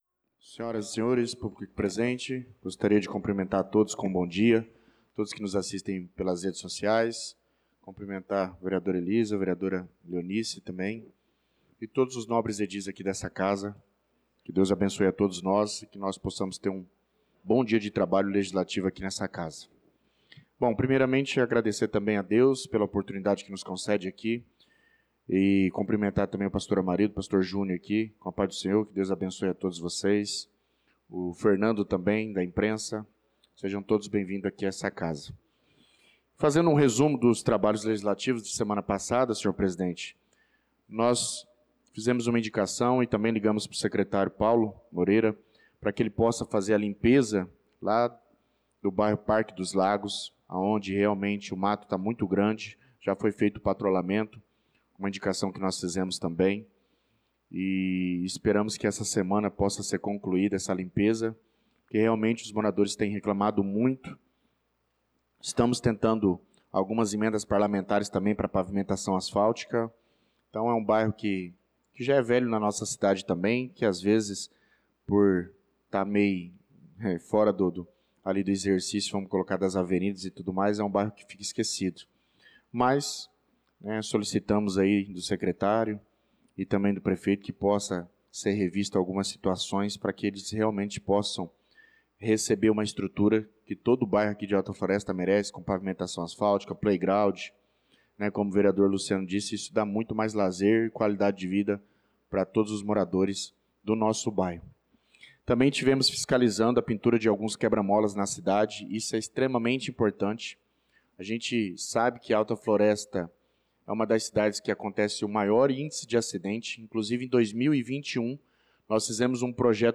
Pronunciamento do vereador Douglas Teixeira na Sessão Ordinária do dia 09/06/2025